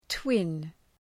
Προφορά
{twın}